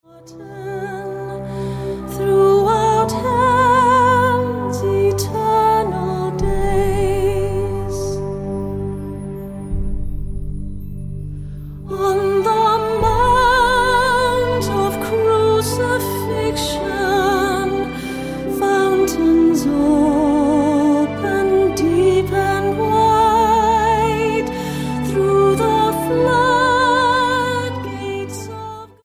STYLE: MOR / Soft Pop